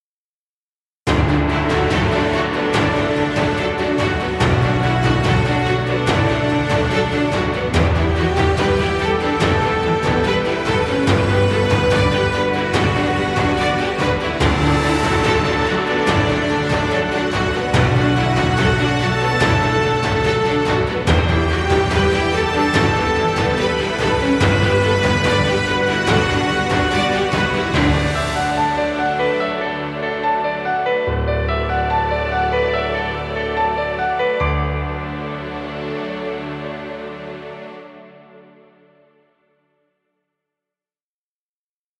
Inspirational epic cinematic music.